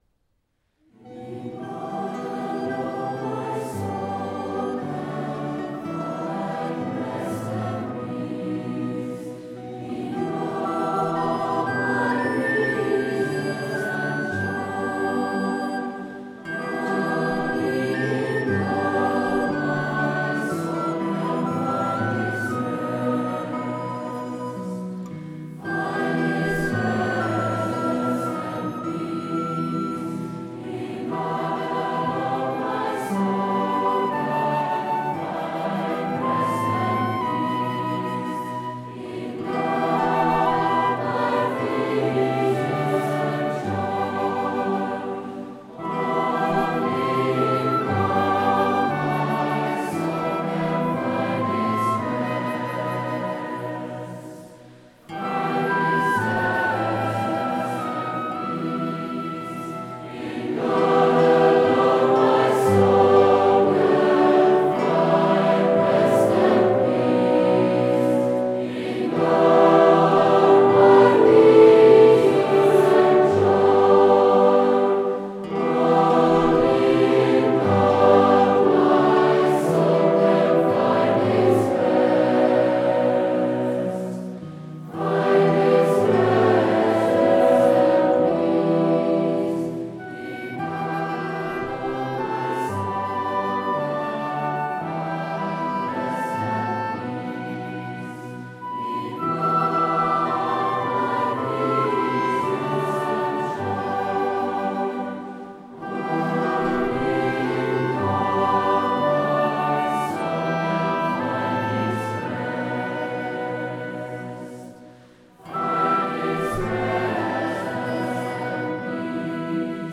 This is why the music of Taize creates such powerful worship experiences.
Each chant refrain is a few simple lines sung in repetition.